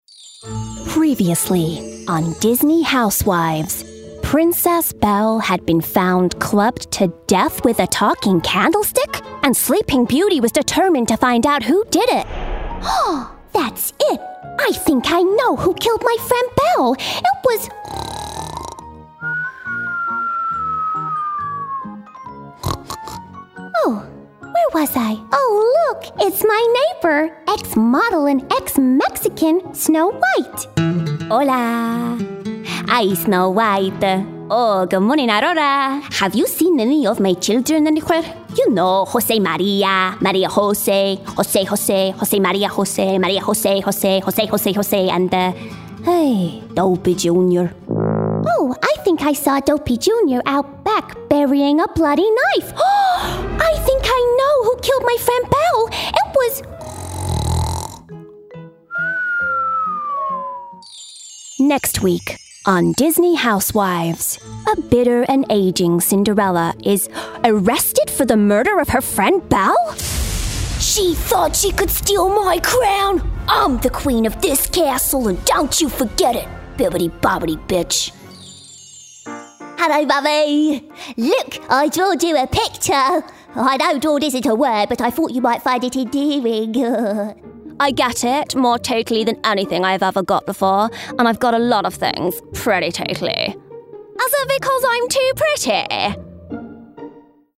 Showreel
Commercial Showreel Talk Talk MullerCorner BritishAirways Nivea Minors In Designers – Promo Perfume – Documentary Minors In Designers – Promo Character Reel Driving Over Lemons Showreel